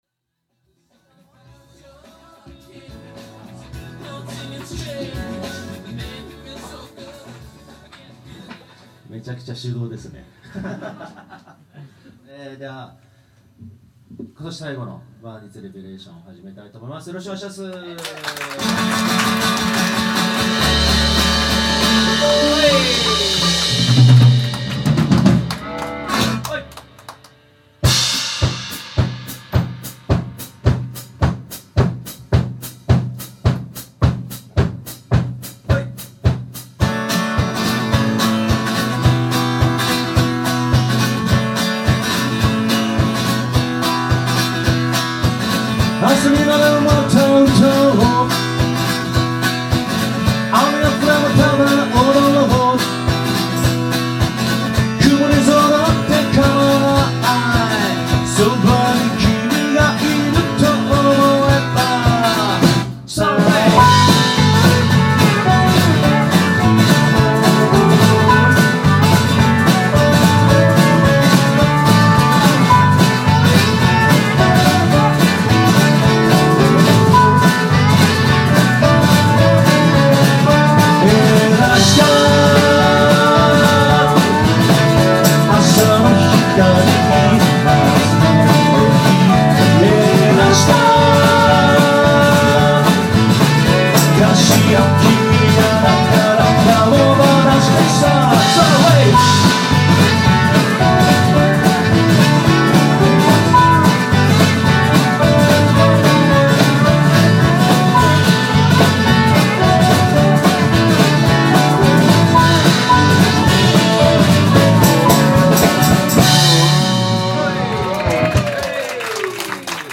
Percussion
Sax
Bass
E.Guitar
Drums